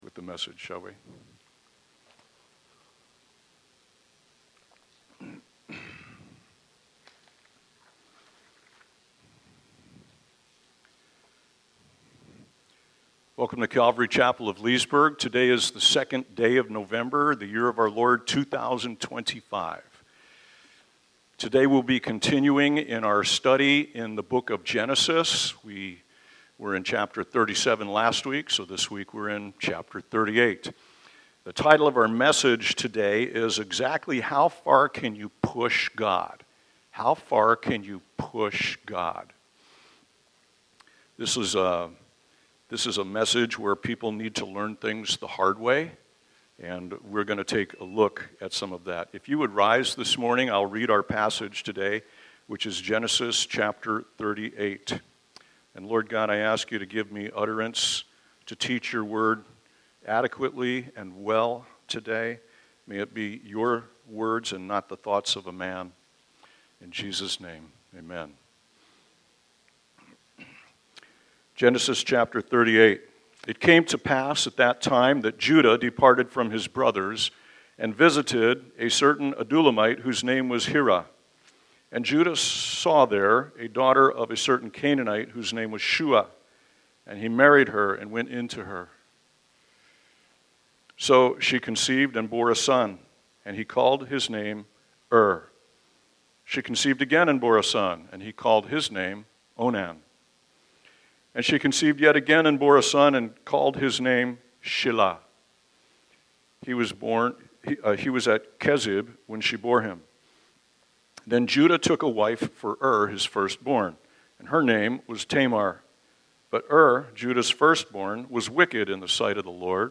by Calvary Chapel Leesburg | Nov 2, 2025 | Sermons